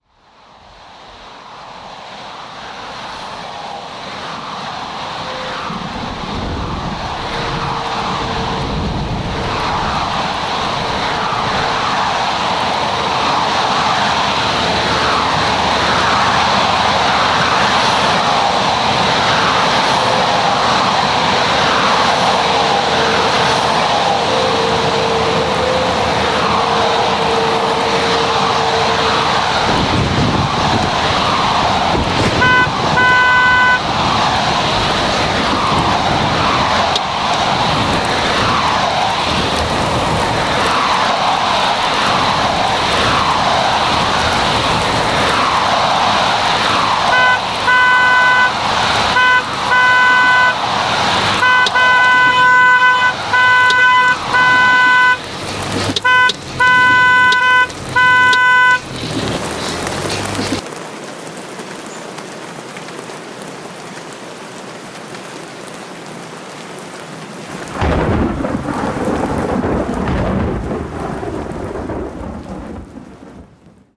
Nous avons discuté un peu et j'ai enregistré notre dialogue :